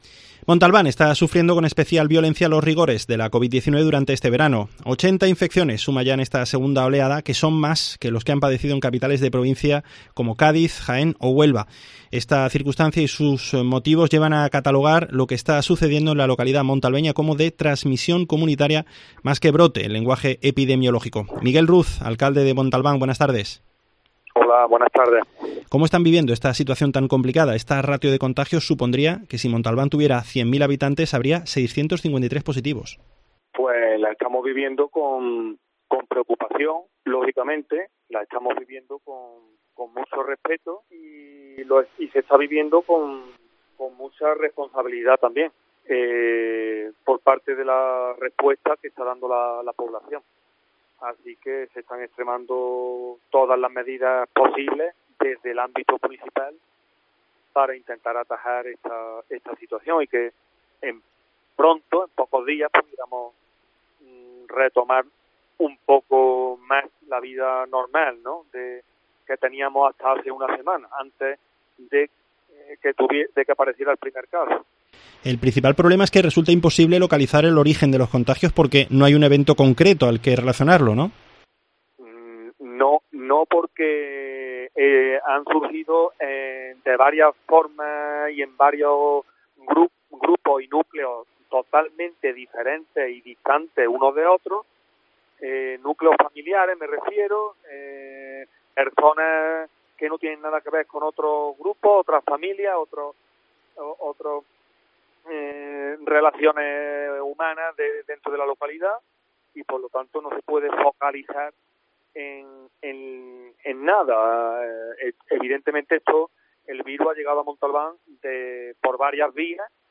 Su alcalde, Miguel Ruz, agradece en COPE la predisposición de los habitantes de la localidad, que decidieron confinarse en sus casas voluntariamente